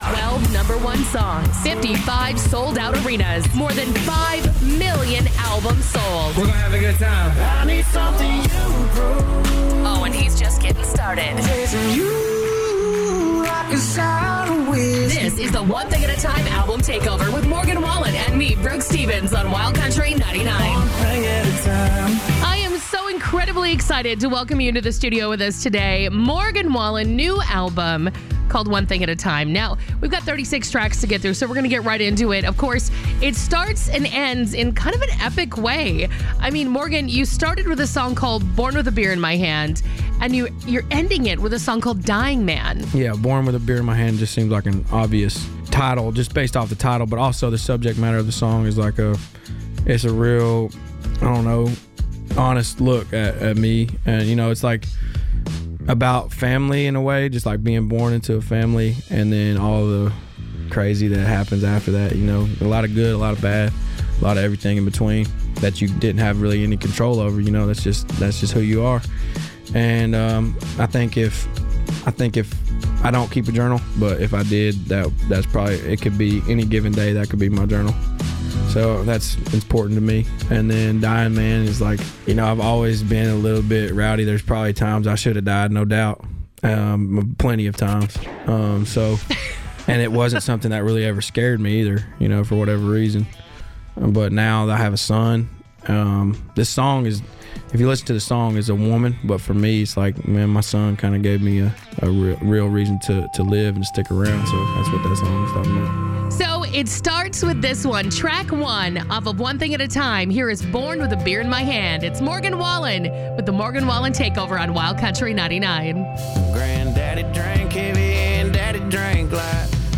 American English Speaking Female Voiceover Artist & Broadcaster
Radio Promo Imaging & Show Hosting - Morgan Wallen Takeover
American, Minnesotan/Midwestern
Middle Aged